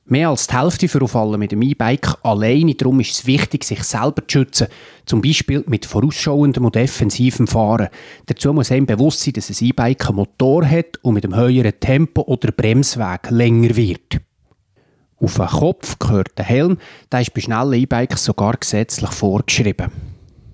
O-Ton zum Download
Die BFU bietet als Service einige Aussagen der Medienmitteilung als O-Ton zum Download an.